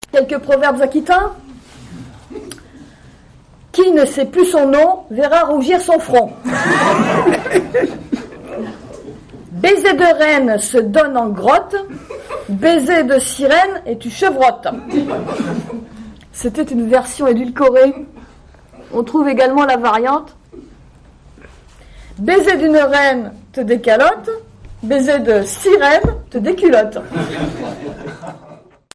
Lecture publique donnée le samedi 27 octobre 2001 par